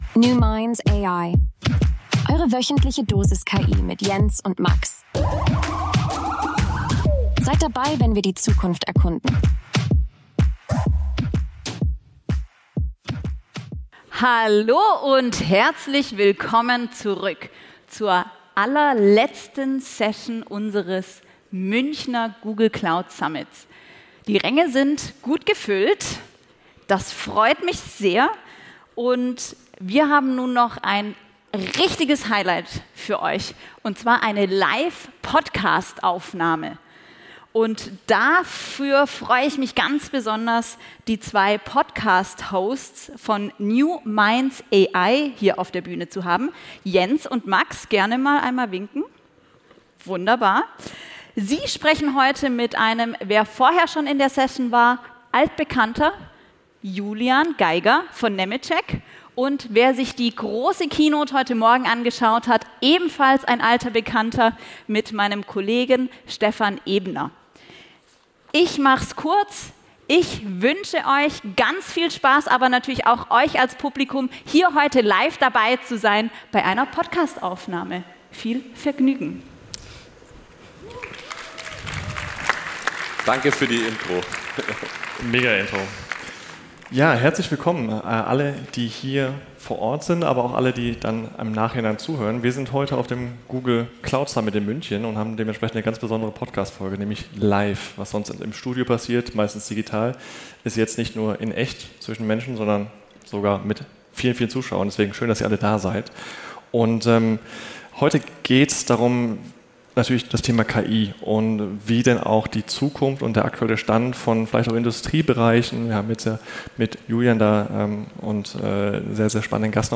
Live von der Google-Bühne: KI, Bau und die neue Kreativität